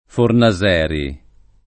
[ forna @$ ri ]